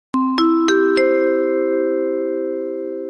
call_tone.mp3